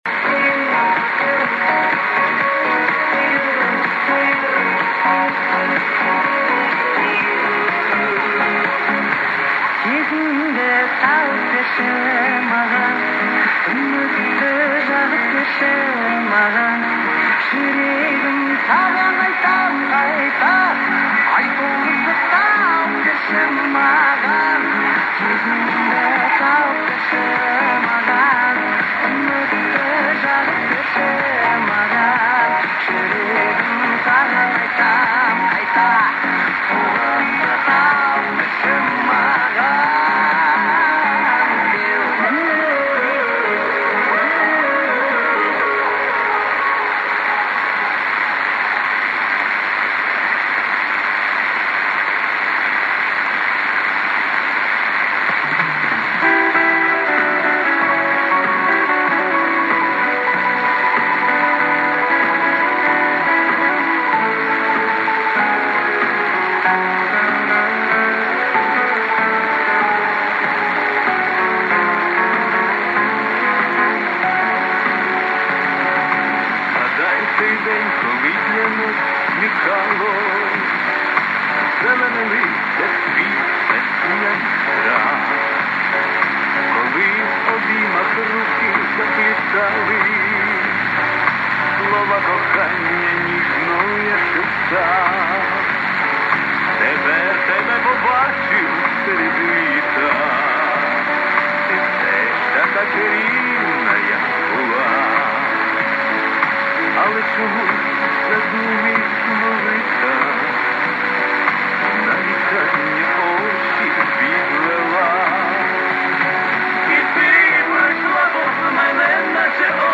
запись эфира 1503кГц неизвестная станция